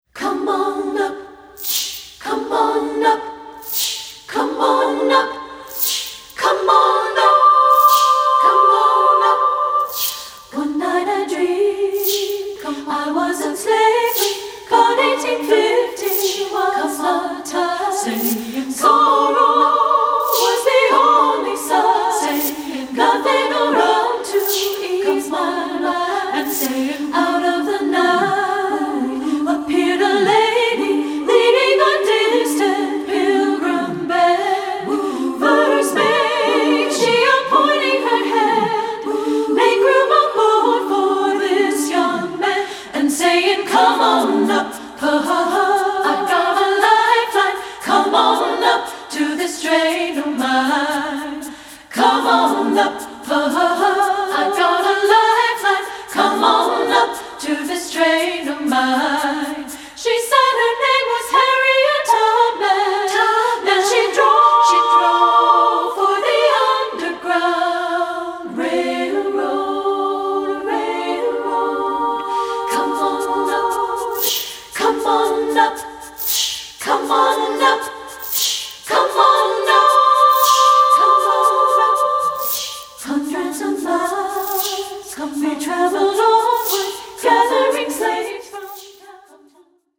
Choral Spiritual Women's Chorus
SSAA A Cap